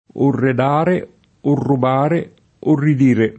redo [ r $ do ]